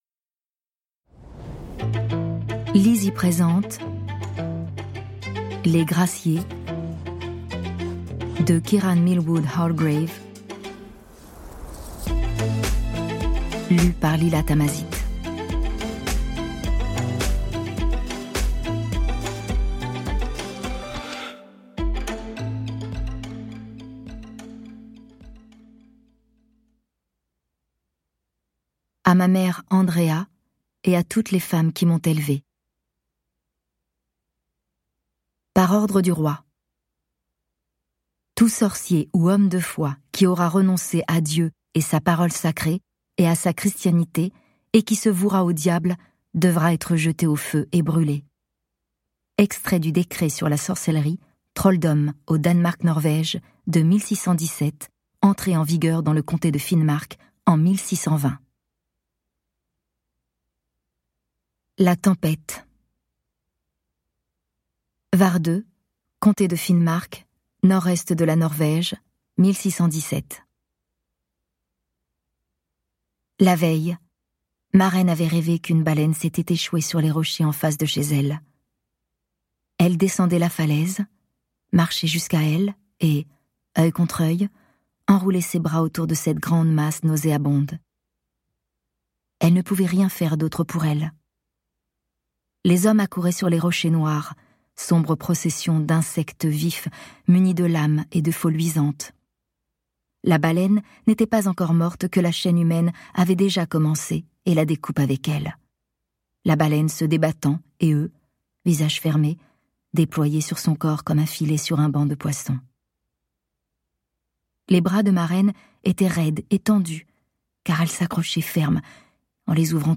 Click for an excerpt - Les Graciées de Kiran Millwood hargrave